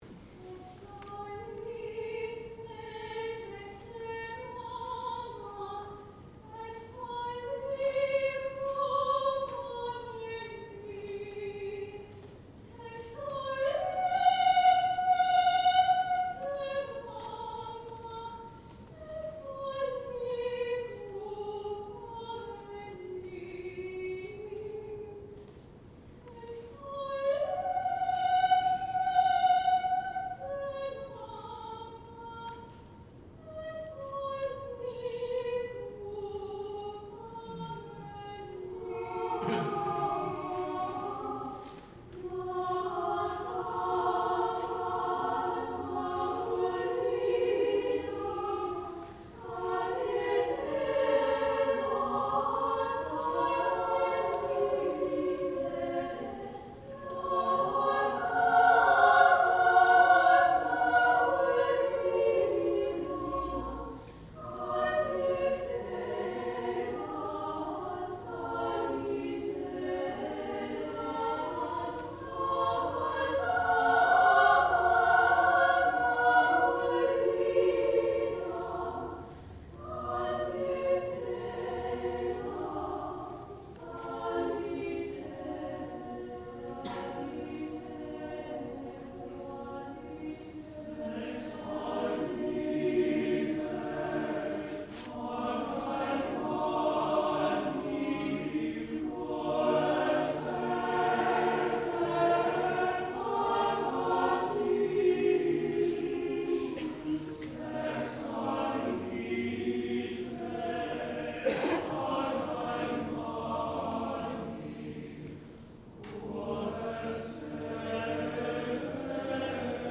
Composer: Latvian Folk Song
Voicing: SATB divisi a cappella